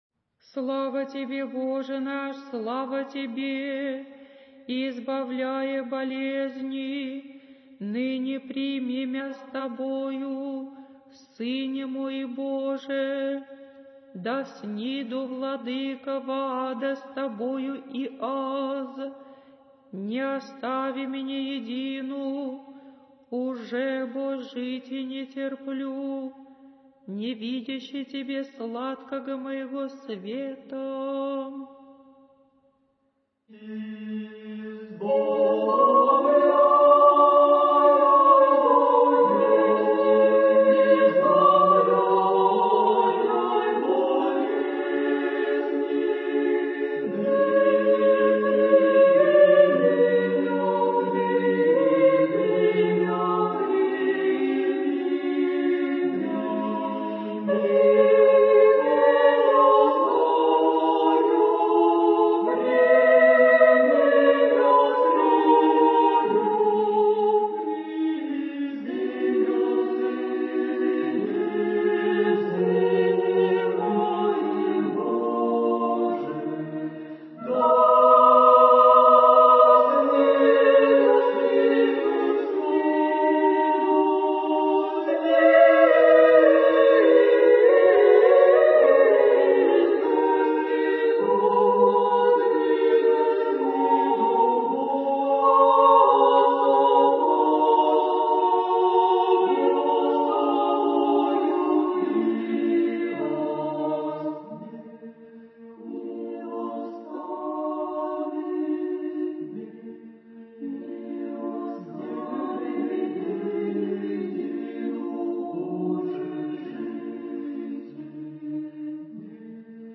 Духовная музыка
Архив mp3 / Духовная музыка / Русская / Хор Свято-Троицкого Ново-Голутвина монастыря / Канон о Распятии и на плач Богородицы /